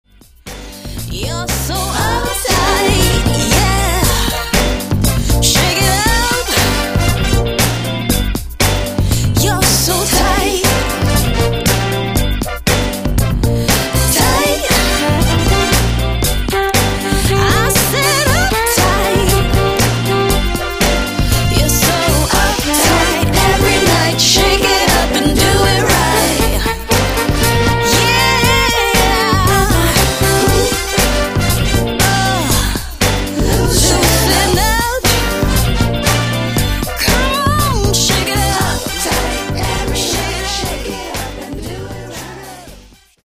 pop/r&b
contemporary sounds of guitar-based pop rock with
smooth and sexy r&b rhythms and reggae-tinged